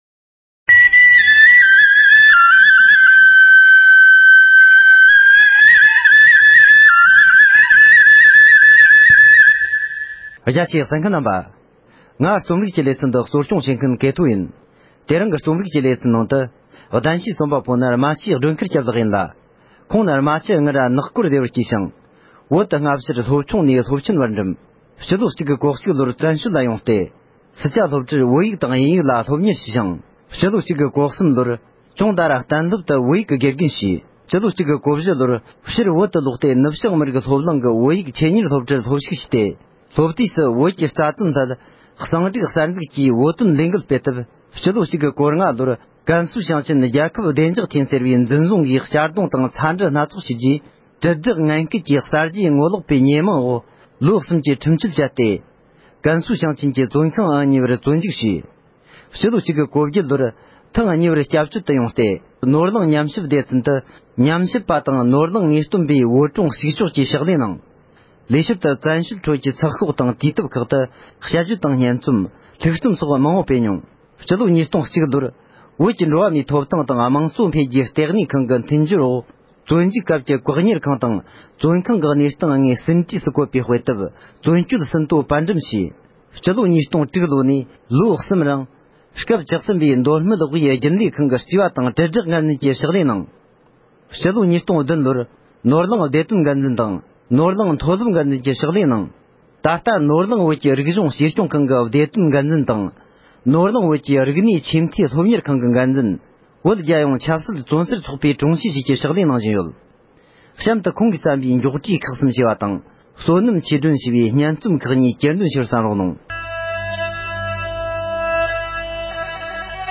ཁོང་གི་སྙན་རྩོམ་གྱེར་འདོན་བྱས་པ།